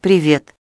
go-speech - TTS service